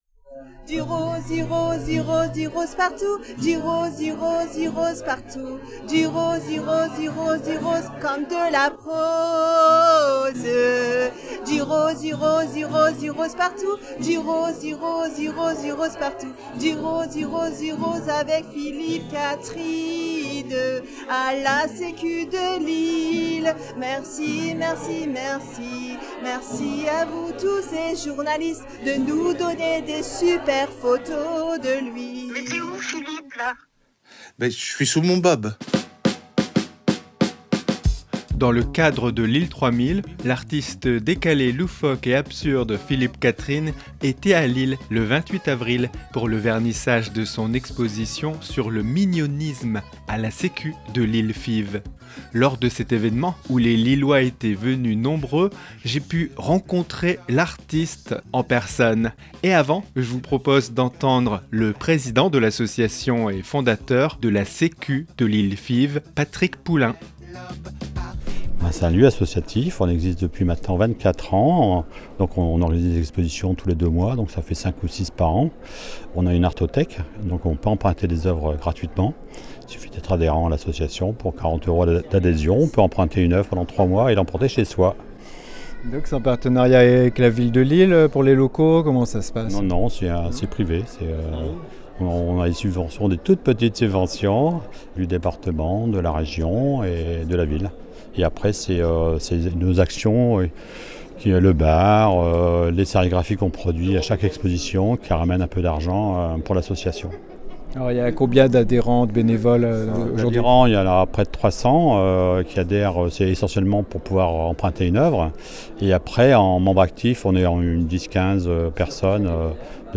Lors de cet événement où les lillois étaient venus nombreux, rencontre avec :